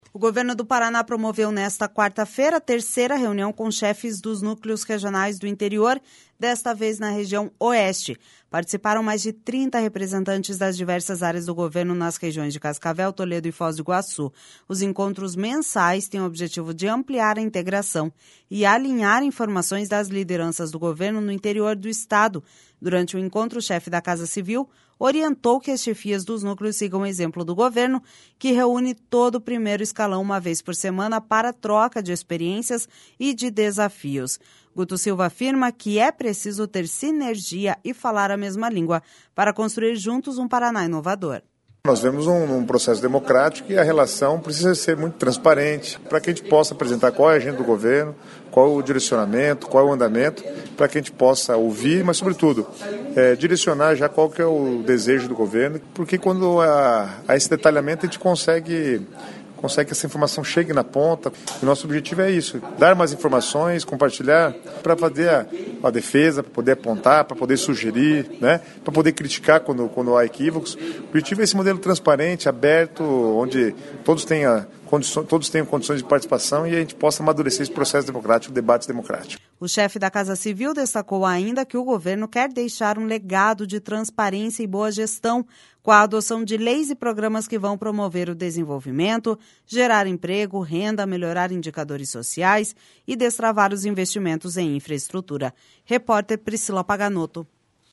Guto Silva afirma que é preciso ter sinergia e falar a mesma língua para construir, juntos, um Paraná inovador.// SONORA GUTO SILVA//O chefe da Casa Civil destacou, ainda, que o governo quer deixar um legado de transparência e boa gestão, com a adoção de leis e programas que vão promover o desenvolvimento, gerar emprego, renda, melhorar indicadores sociais e destravar os investimentos em infraestrutura.